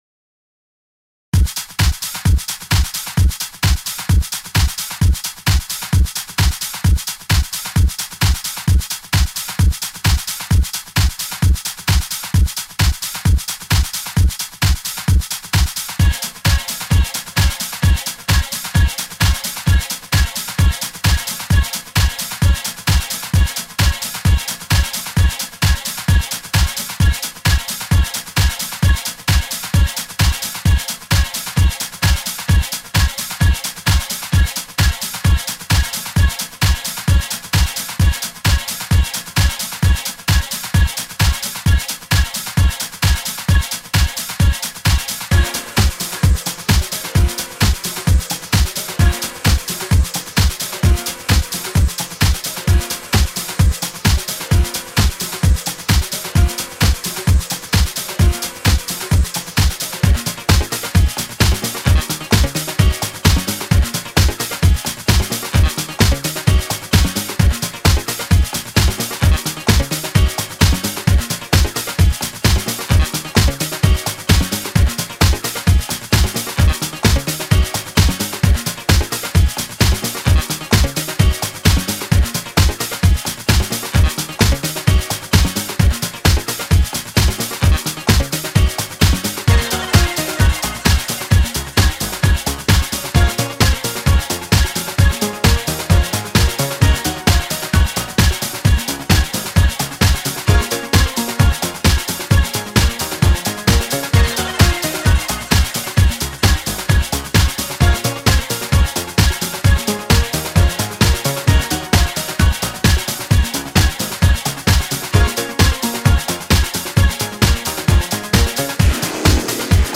Electronic Disco Music
a frenetic paced dance music that flows beautifully.